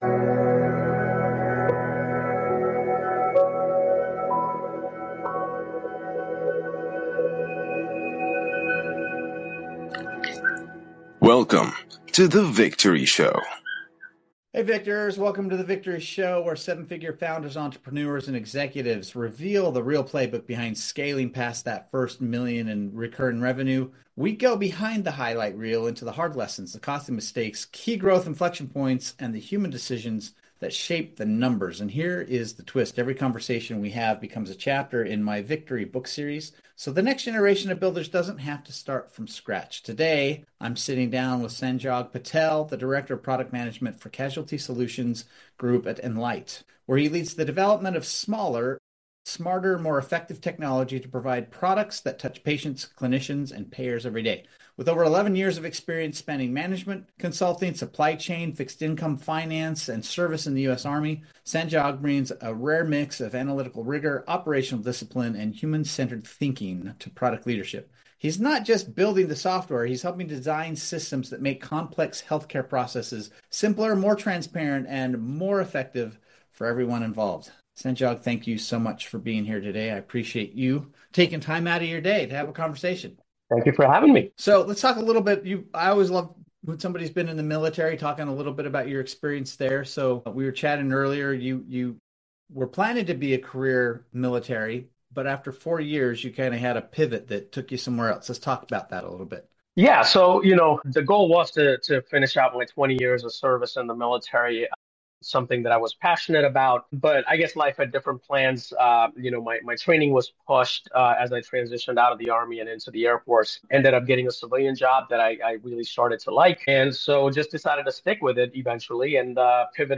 Leadership Lessons